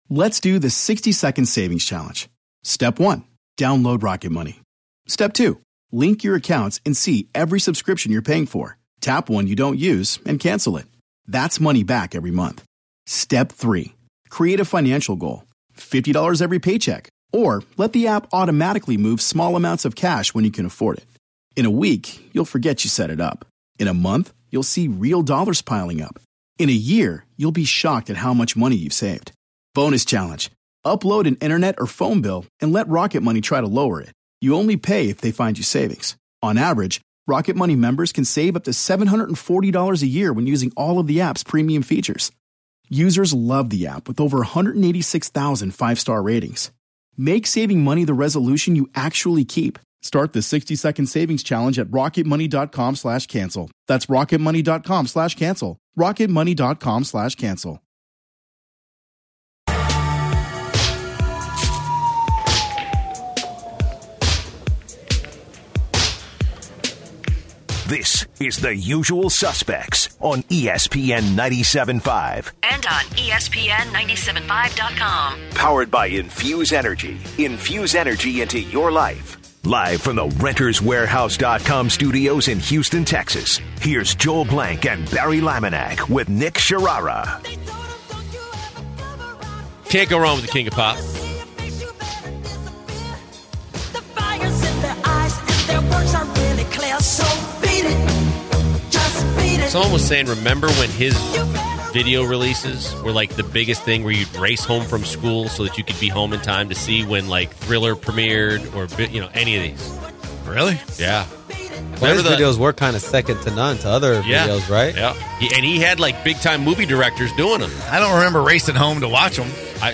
In hour two, the guys take listeners calls on the NFL Draft, the guys music selection, and the MVP race.